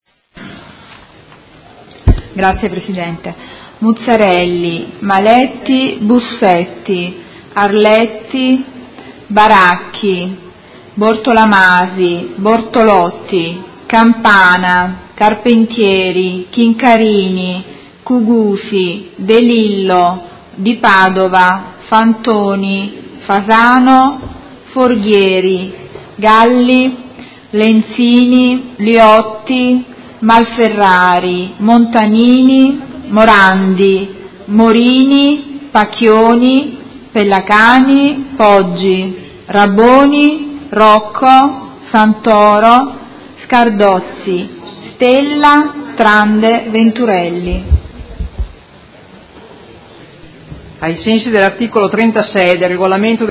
Segretaria — Sito Audio Consiglio Comunale
Seduta del 13/07/2017 Appello.